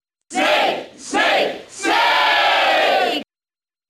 File usage The following 3 pages use this file: List of crowd cheers (SSBB)/English Snake (SSBB) File:Snake Cheer NTSC Brawl.ogg Transcode status Update transcode status No transcoding required.
Snake_Cheer_English_SSBB.ogg